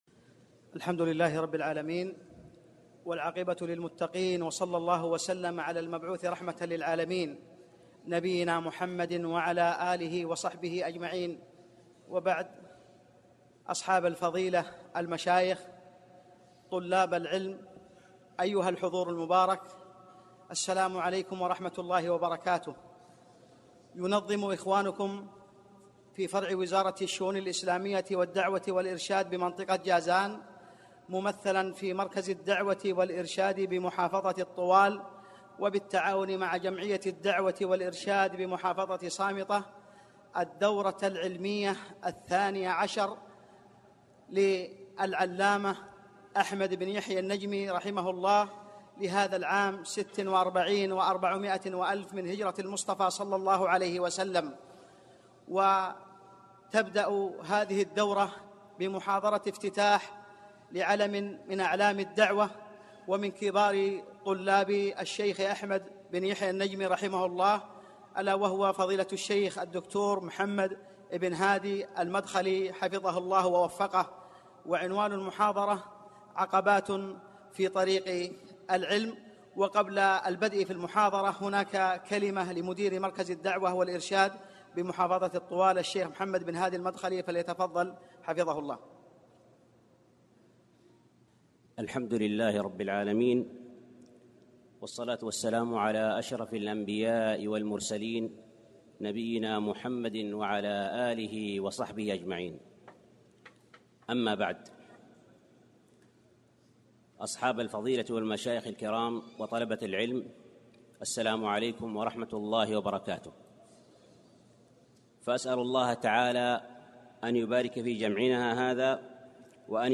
المحاضرة
al-mahadhura-al-afttahia.mp3